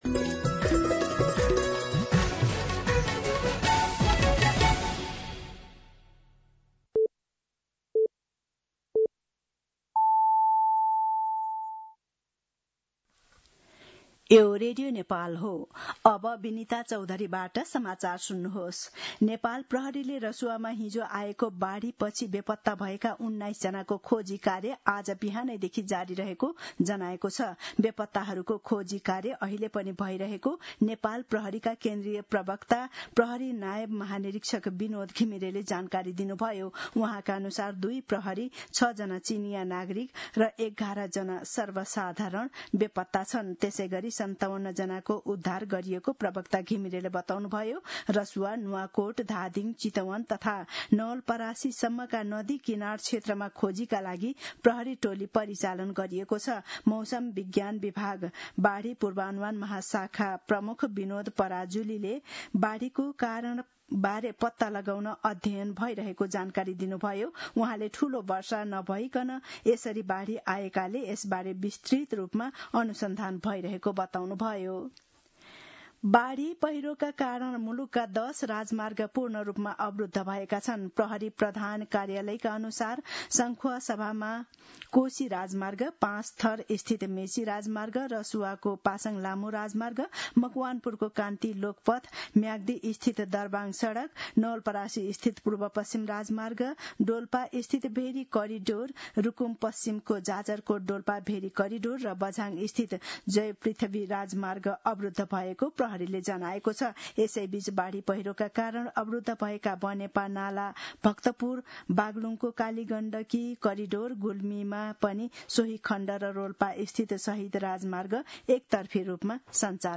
An online outlet of Nepal's national radio broadcaster
दिउँसो १ बजेको नेपाली समाचार : २५ असार , २०८२